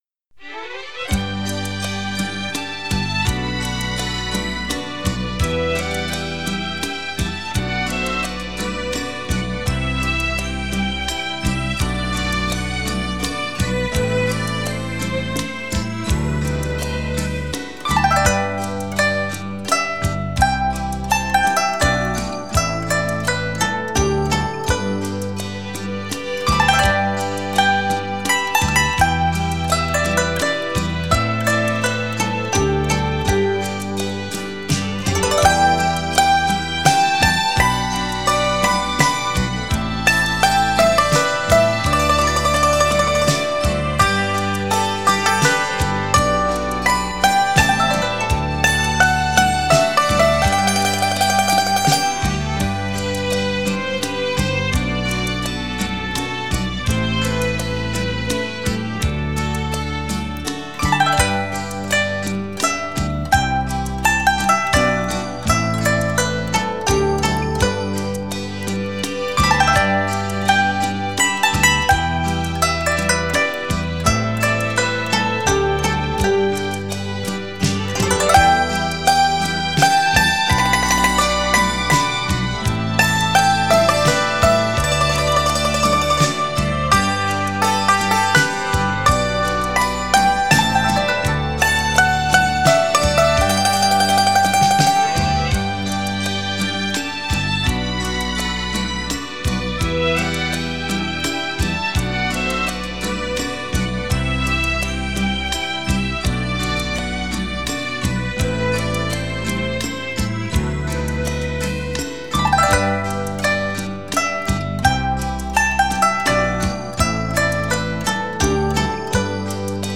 美妙绝伦天籁琴音
脉脉深情流动旋律
典雅、清丽的乐音将带您体会那份久远的浪漫深情